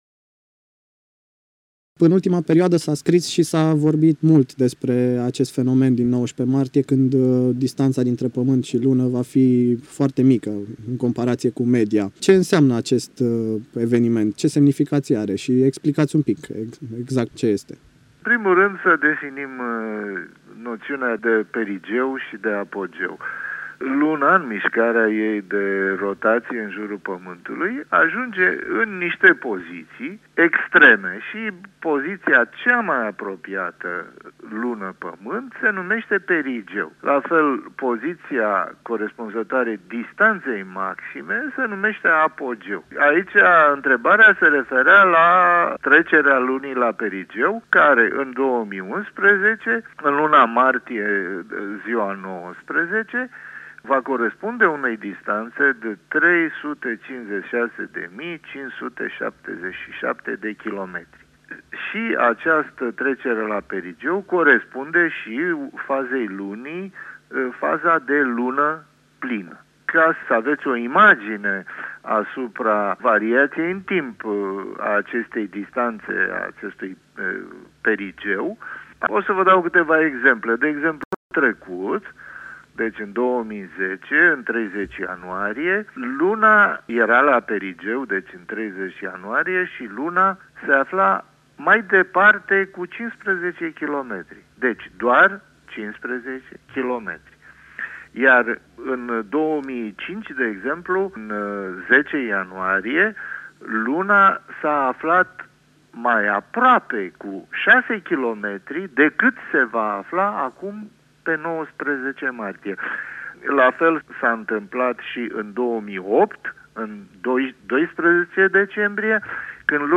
INTERVIU AUDIO Demontarea unor scenarii alarmiste legate de momentul de apropiere a Lunii de Pamant